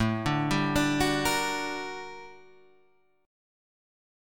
GmM7/A chord {5 5 5 7 7 6} chord